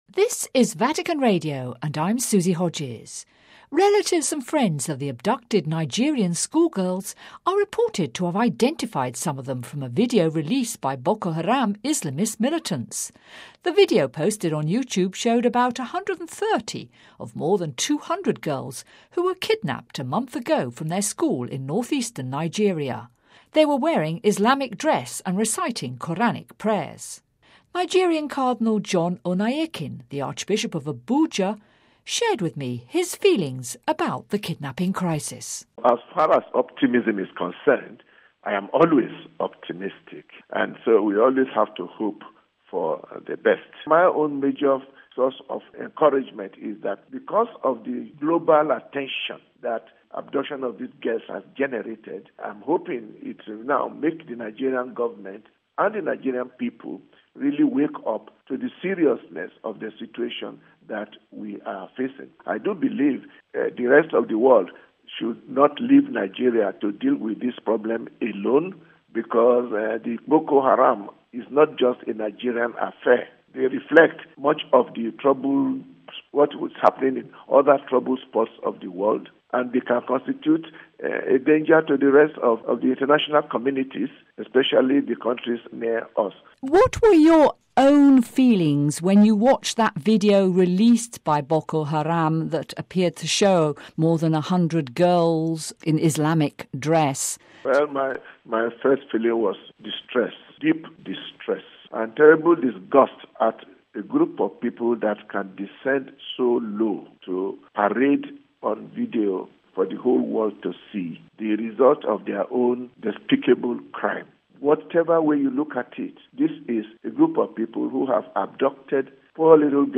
Listen to the full interview with Cardinal John Oneiyekan: RealAudio